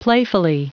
Prononciation du mot playfully en anglais (fichier audio)